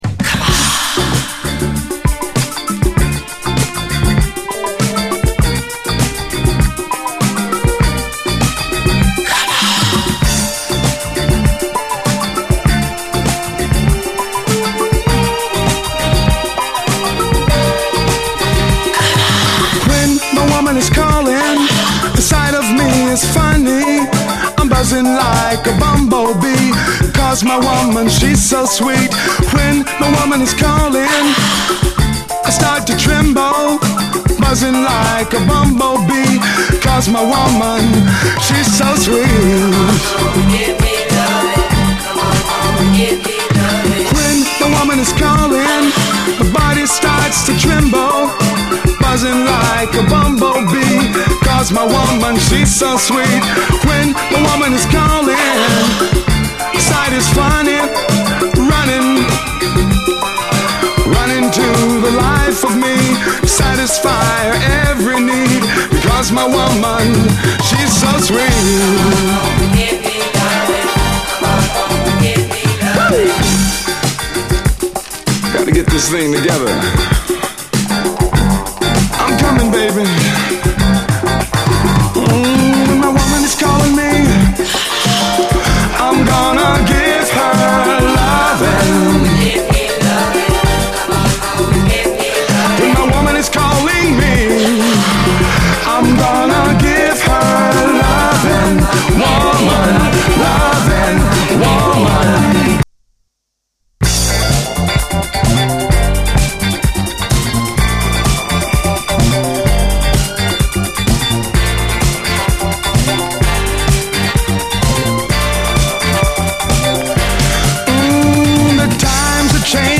SOUL, 70's～ SOUL, 7INCH
無敵のユーロ産キラー・モダン・ソウル〜レアグルーヴ！ネットリと濃密な妖しいムード、唯一無二のスーパー・トラック！
ネットリとエロティックかつ黒々とダーク。
イントロの女性コーラスの囁きからKOされます！